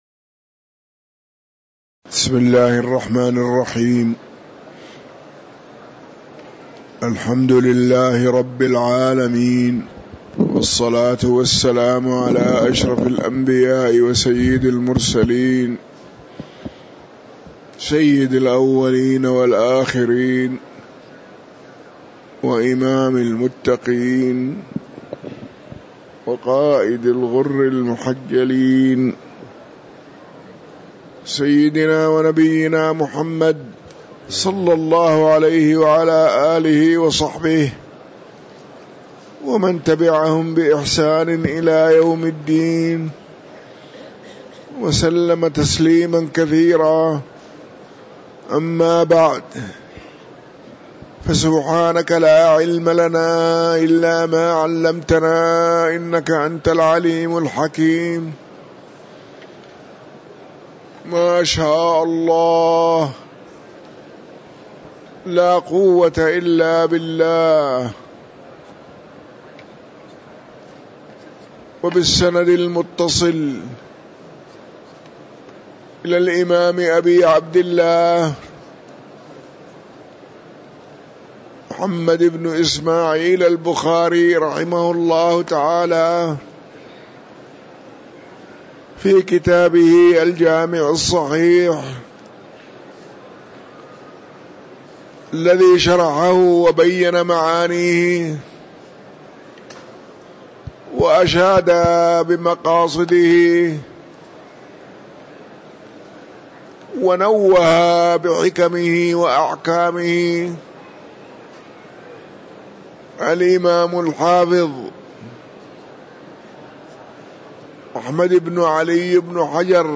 تاريخ النشر ٢١ محرم ١٤٤٥ هـ المكان: المسجد النبوي الشيخ